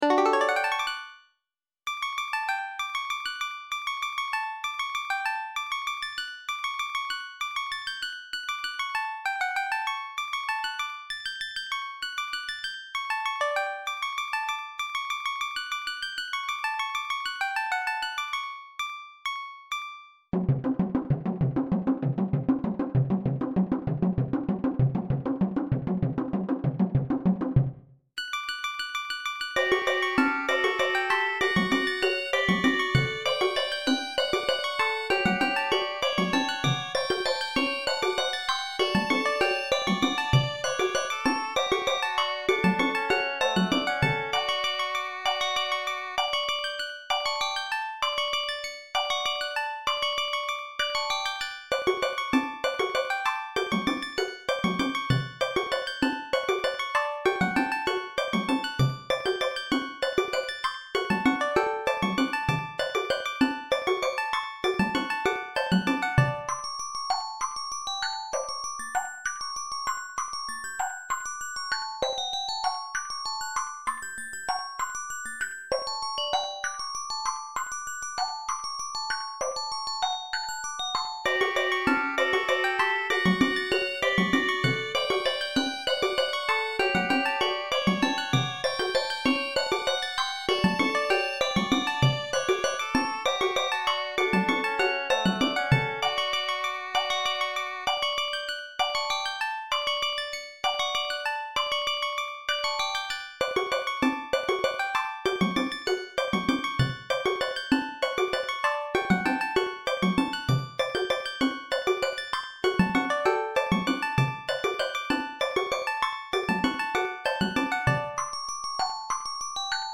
Cute upbeat song to replace that one ocean song that's like 6 minutes of whale noises.